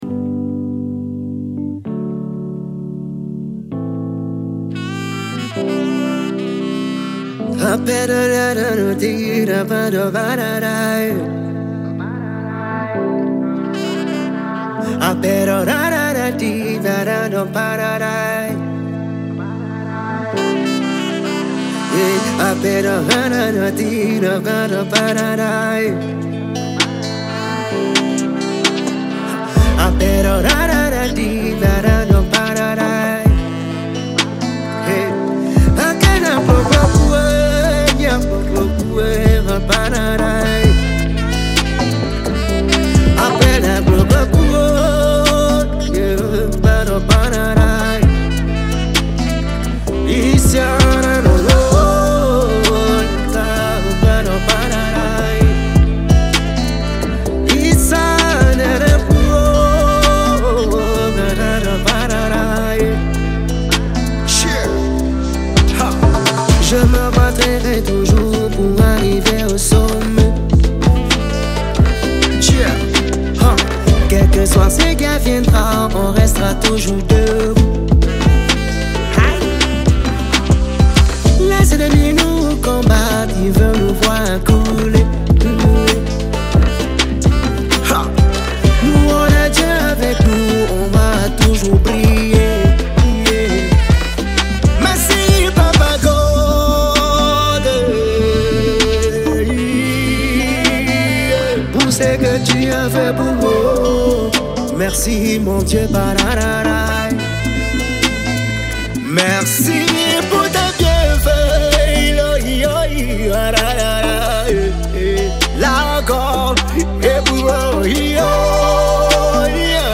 | World music